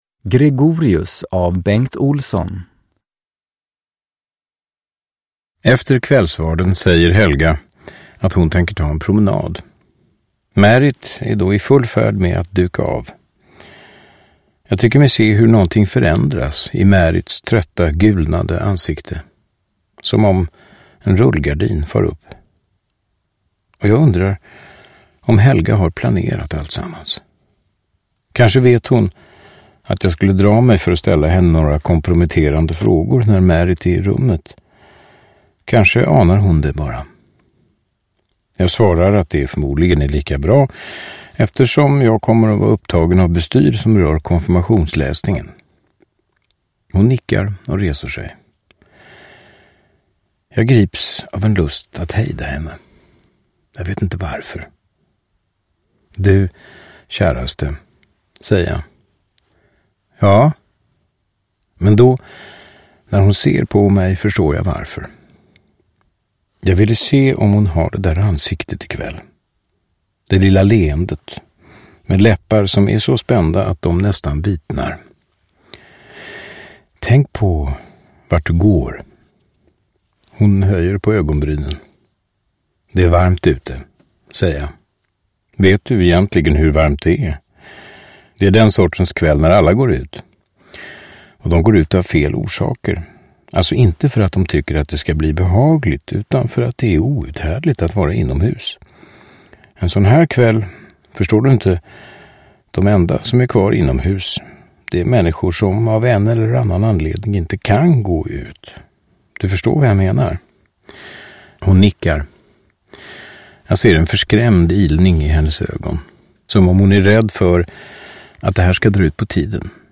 Gregorius – Ljudbok – Laddas ner
Uppläsare: Johan Rabaeus